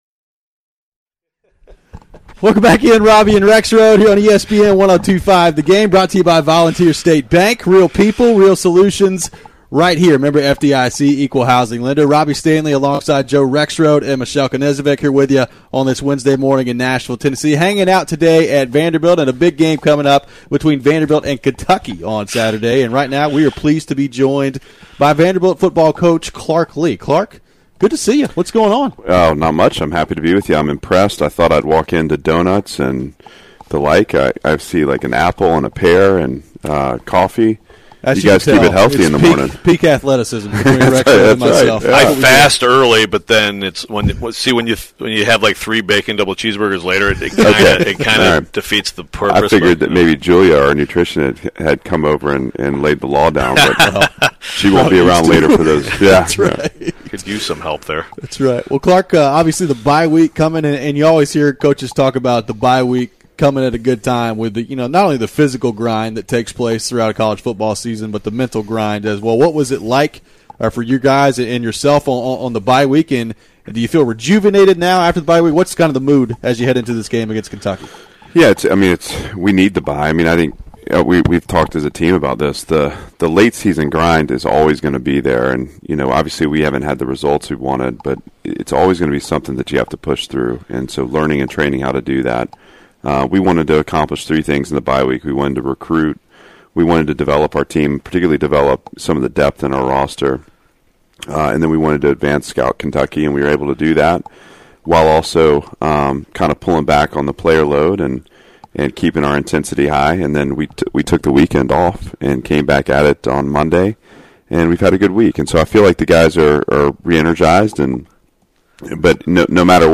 in person at the Vanderbilt field.